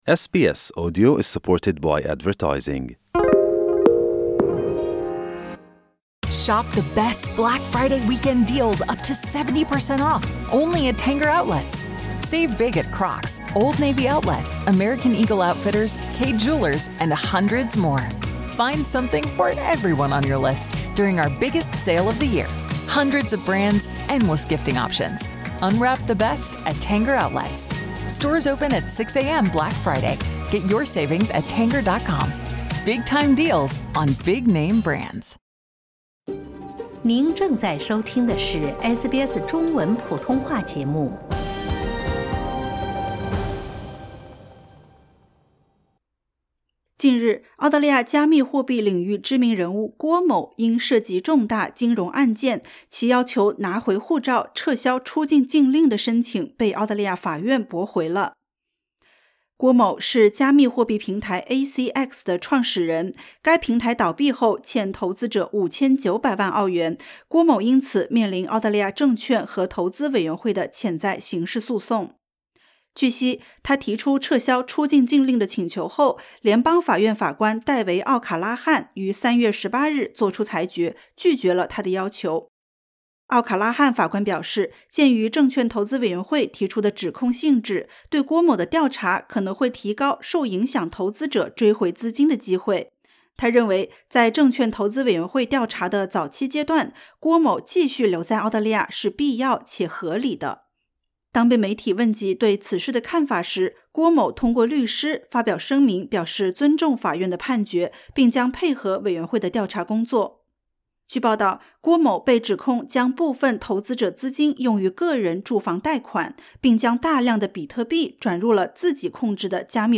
近日，澳大利亚加密货币平台华人负责人因涉及重大金融案件，其要求拿回护照、撤销出境禁令的申请被澳大利亚法院驳回。点击 ▶ 收听完整采访。